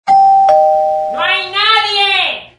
Play, download and share no hay nadie original sound button!!!!
ding_dong_no_hay_nadie.mp3